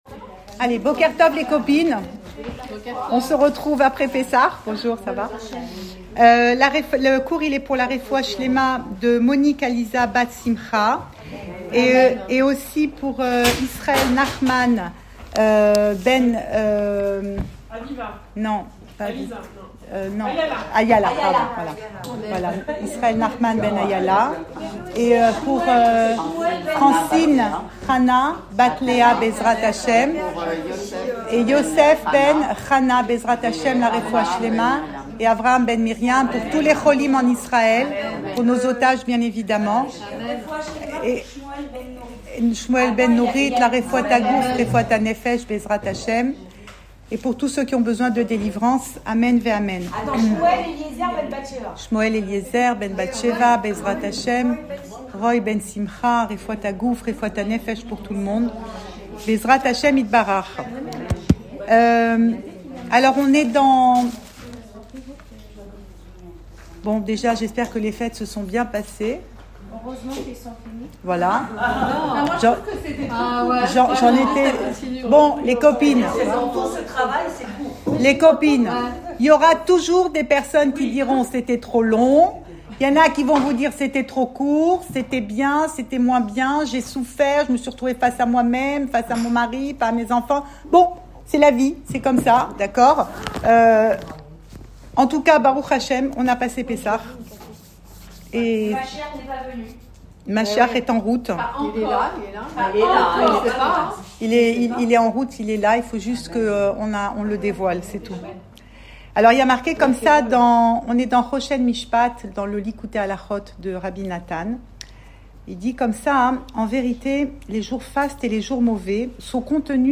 Cours audio Le coin des femmes Le fil de l'info Pensée Breslev - 23 avril 2025 25 avril 2025 Un jour « mauvais ». Enregistré à Tel Aviv